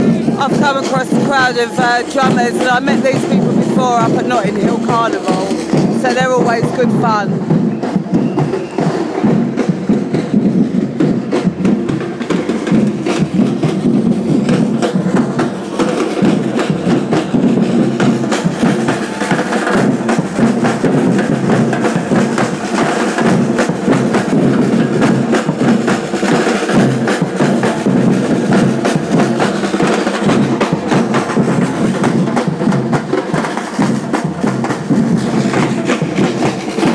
Sounds of the marathon